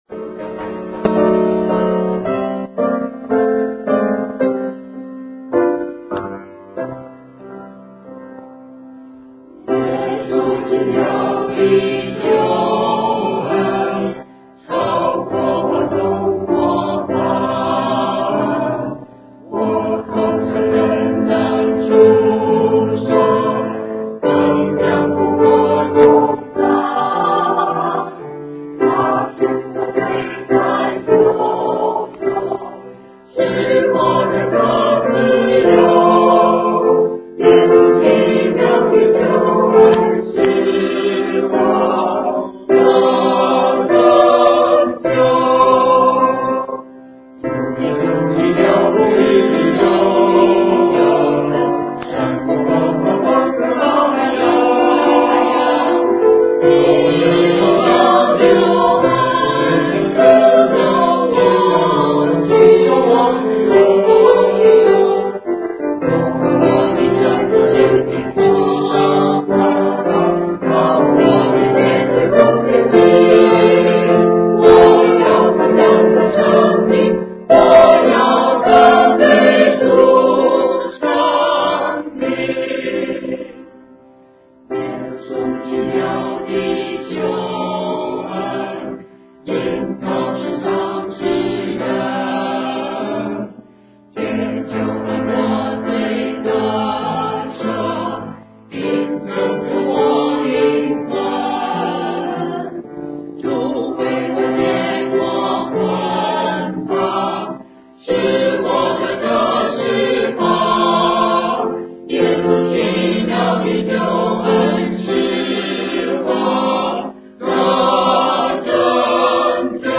6/3/2012 - Fellowship Sharing 分享見證
Choir Presentation